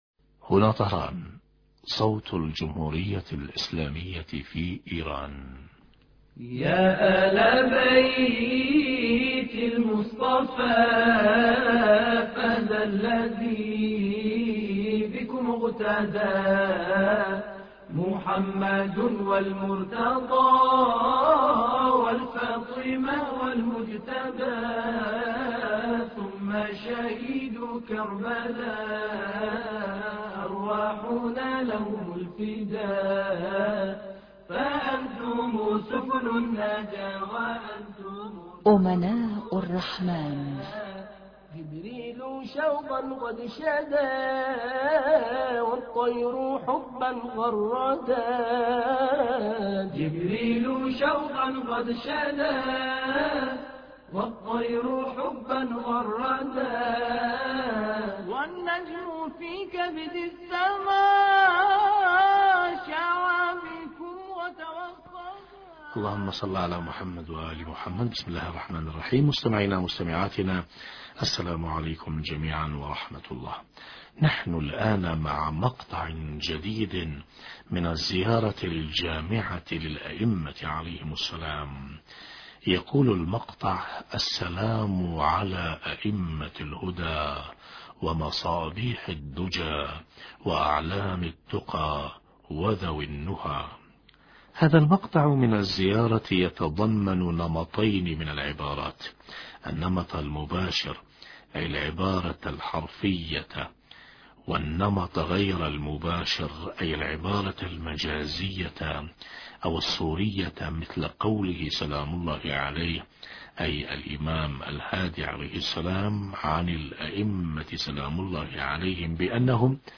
أما الآن نتابع تقديم برنامج امناء الرحمن بهذا الاتصال الهاتفي مع سماحة